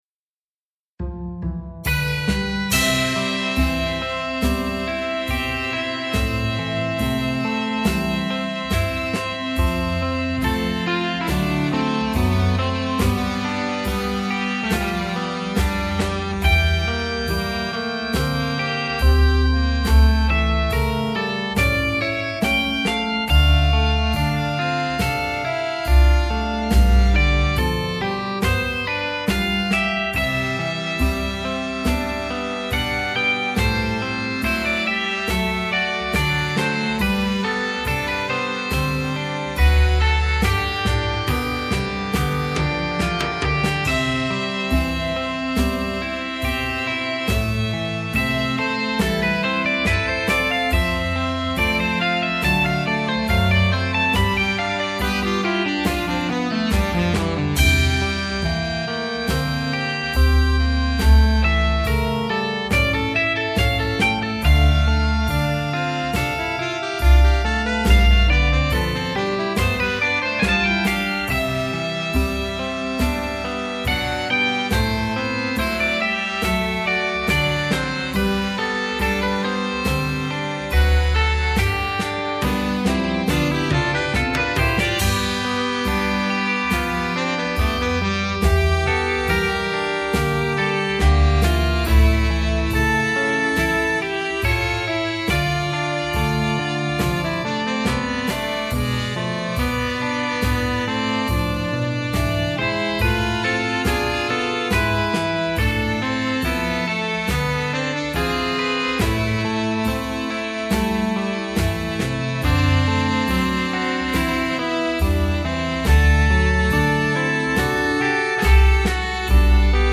Instrumentation: Ten. Sax, Solo Gtr,
(opt.Rhythm Gtr)
Keyboards, Bass, Drums
A light jazz flavoured arrangement with
good opportunities for improvisation.